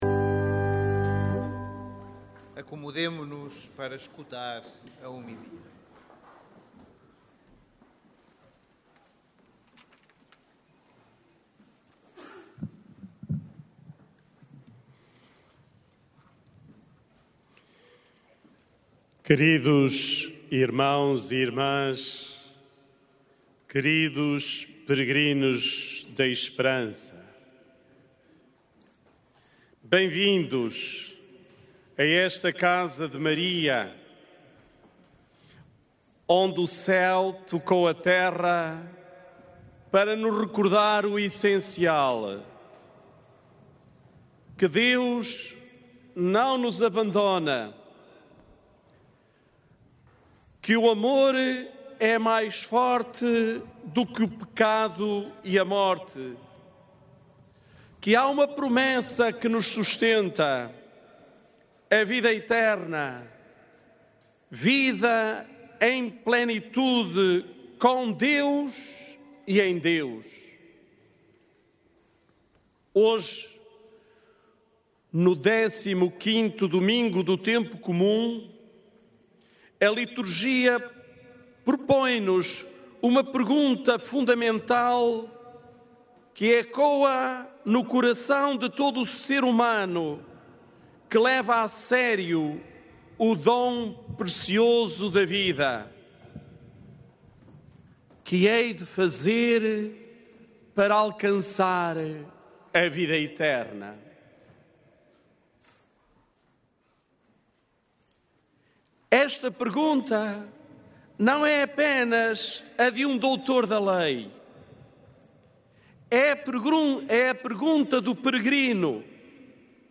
Na homilia da Missa Internacional Aniversária de 13 de julho, celebrada esta manhã no Recinto de Oração do Santuário de Fátima, o bispo das Forças Armadas e de Segurança apresentou a mensagem de Fátima como sinal de esperança, num mundo marcado pelo sofrimento, reforçando a mensagem que deixou na noite anterior.